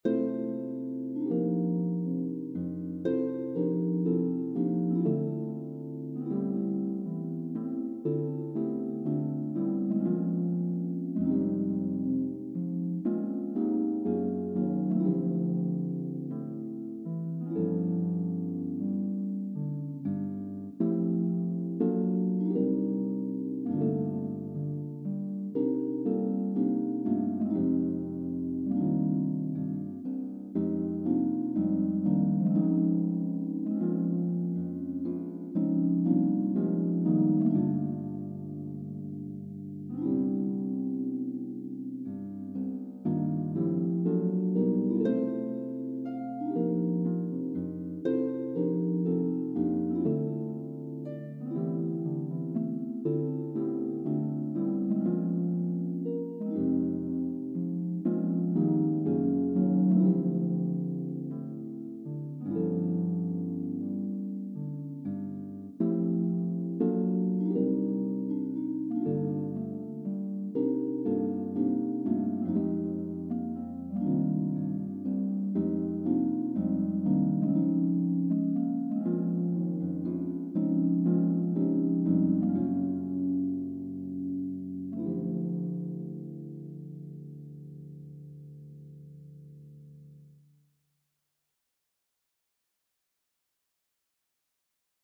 a few more original pieces for solo (jazz) piano
I was getting rather bored with nothing to do, so I thought I would do a bit more writing, even though the soundfiles have to be played by my music notation software.
I recorded this with harp rather than piano as the piano sound on my software is not good. Sounds better with headphones on.